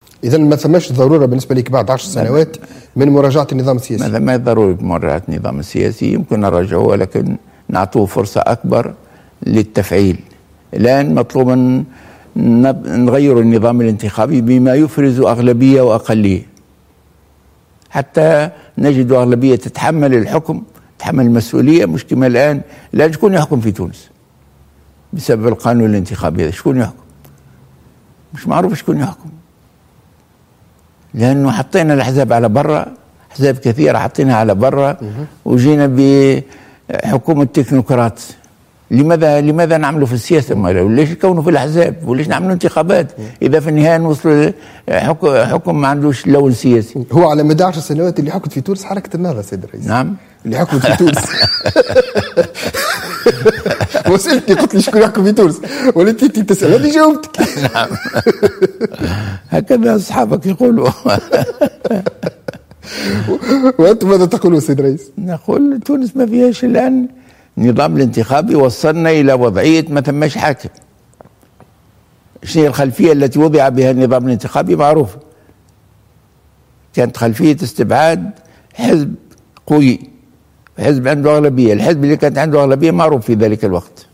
قال رئيس مجلس نواب الشعب في حوار له مساء اليوم الأحد على القناة الوطنية الأولى إنه لا ضرورة لمراجعة النظام السياسي اليوم بعد 10 سنوات ولكن يجب تنقيح النظام الإنتخابي بما يفرز أغلبية تتحمل المسؤولية و أقلية معارضة.